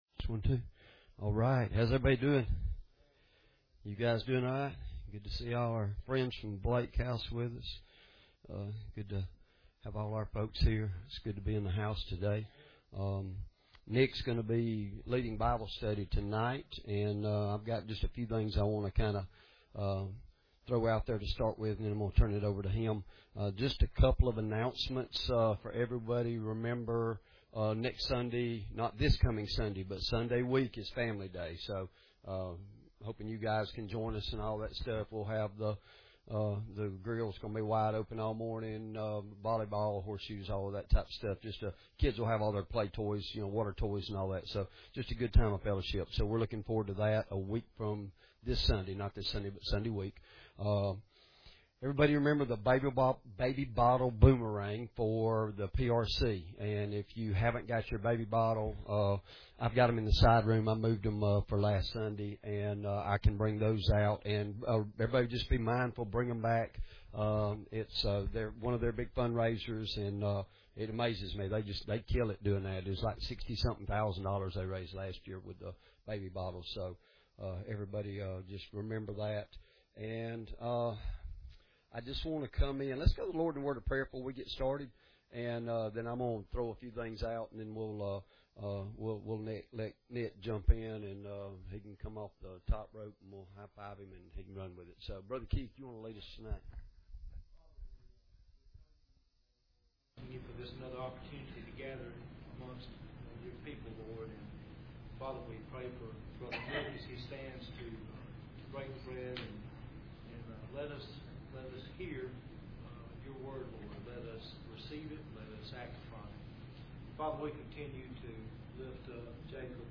Sermon Audio Downloads | Victory Fellowship